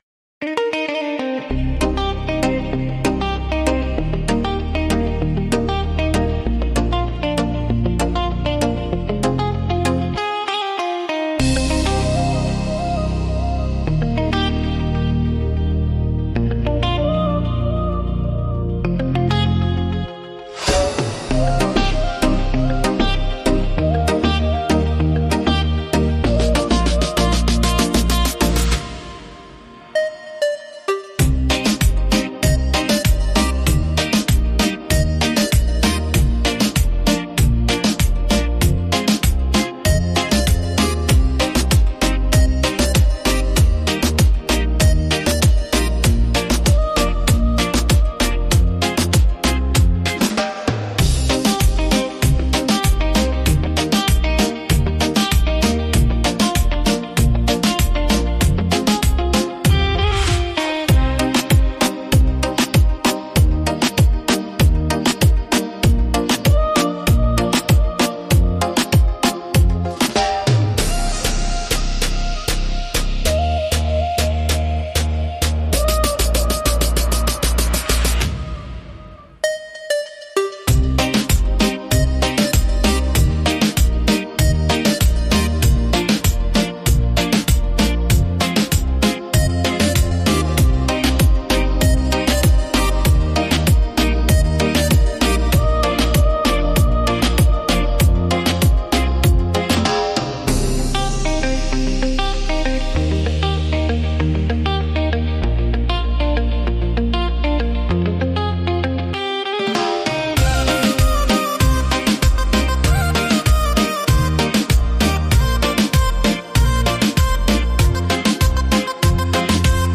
Beat Reggaeton Instrumental
Acapella e Cori Reggaeton Inclusi
• Mix e mastering di qualità studio
D#